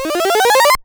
その他の効果音